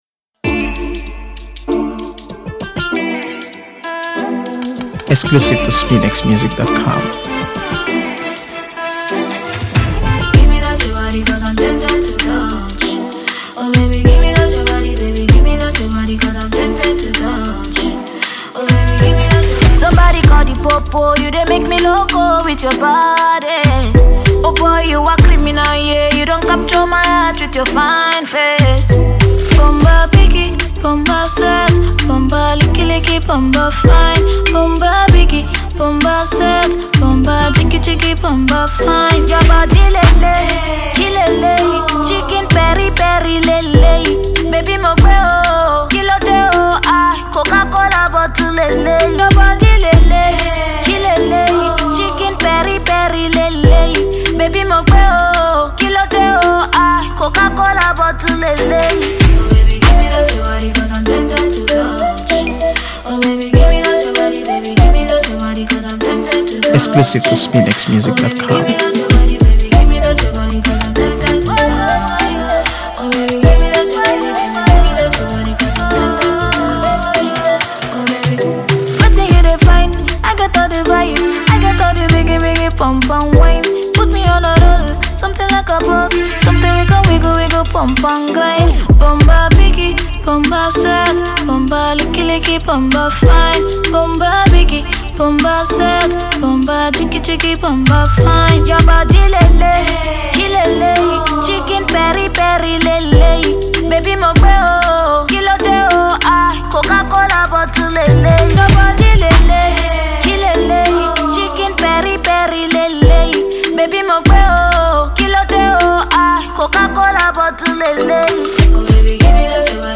AfroBeats | AfroBeats songs
Catchy Tune
Nigerian singer